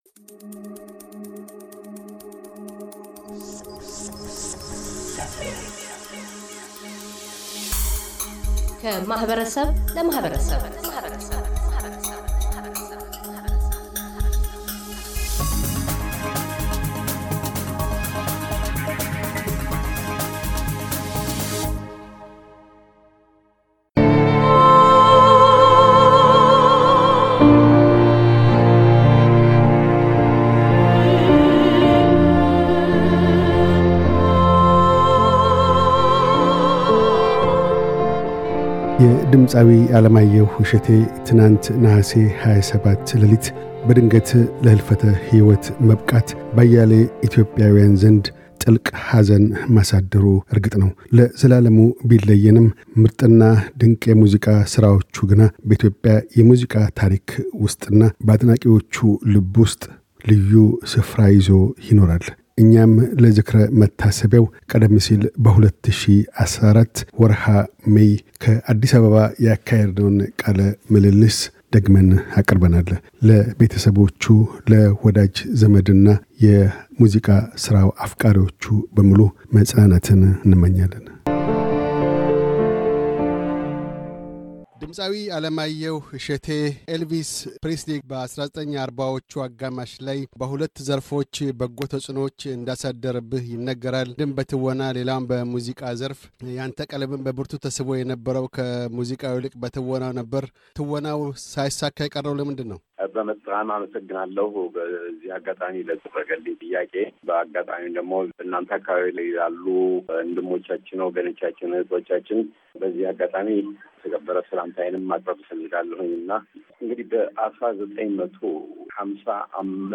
የድምፃዊ ዓለማየሁ እሸቴ ትናንት ነሐሴ 27 ለሊት በድንገት ከዚህ ዓለም መለየት በአያሌ ኢትዮጵያውያን ዘንድ ጥልቅ ሐዘን ማሳደሩ እርግጥ ነው። ለዘላለሙ ቢለየንም ምርጥና ድንቅ የሙዚቃ ሥራዎቹ ግና በኢትዮጵያ የሙዚቃ ታሪክና በአድናቂዎቹ ልብ ውስጥ ልዩ ሥፍራ ይዞ ይኖራል። እኛም ለዝክረ መታሰቢያው ቀደም ሲል በወርኃ ሜይ 2014 ያካሄድነውን ቃለ ምልልስ ደግመን አቅርበናል።